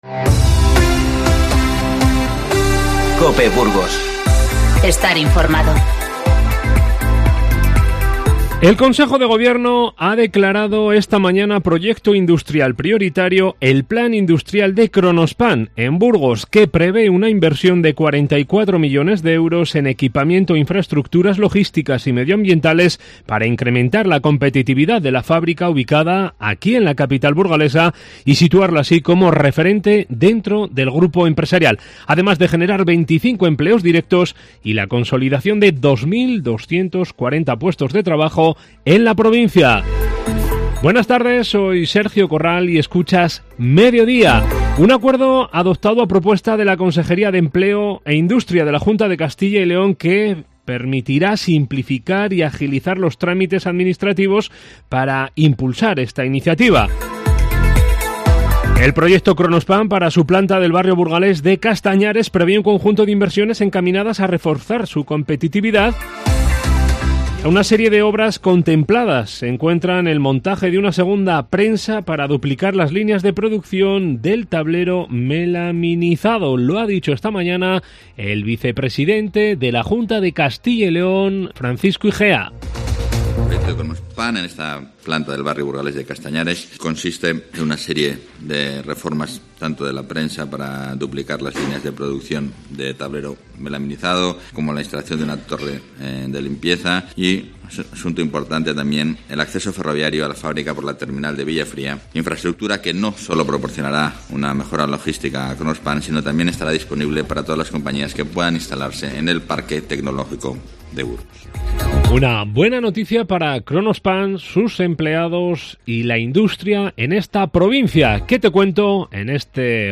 Informativo 13-02-20